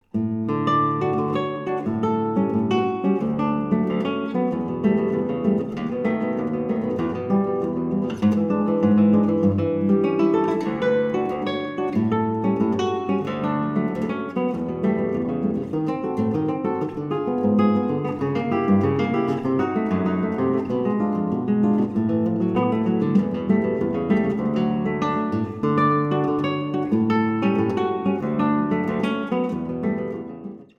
Solo Guitar and Guitar with String Orchestra